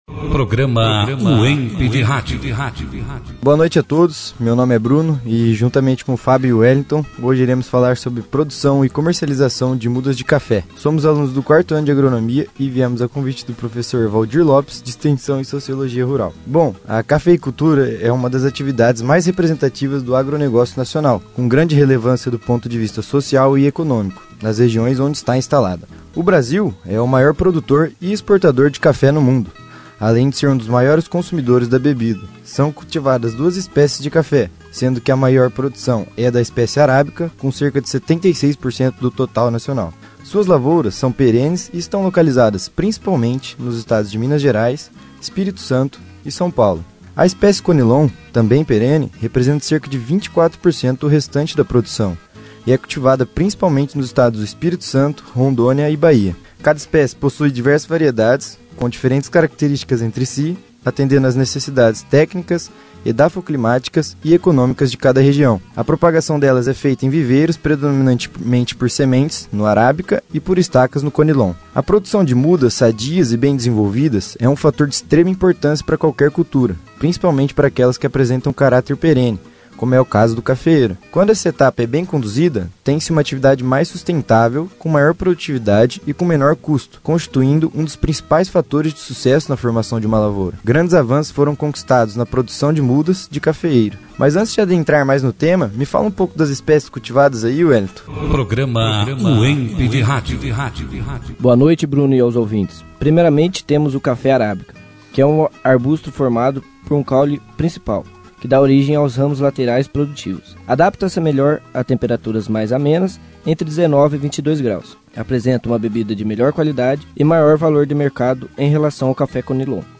Produzido e apresentado pelos alunos, Acadêmicos do 4º ano do curso de Agronomia